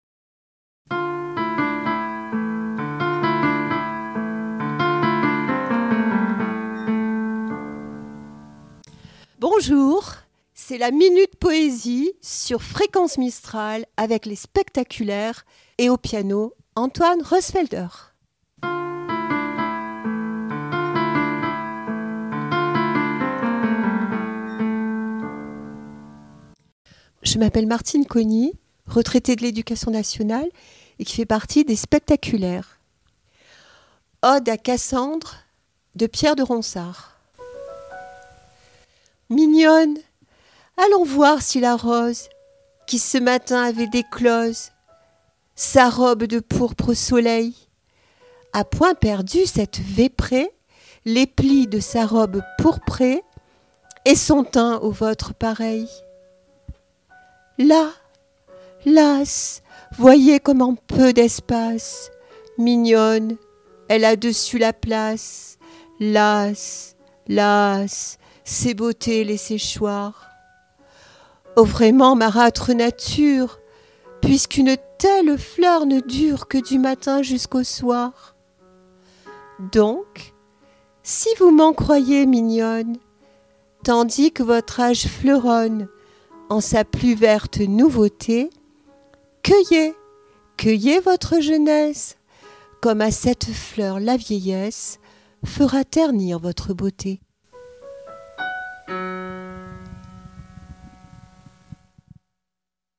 La poésie se murmure au rythme du piano sur Fréquence Mistral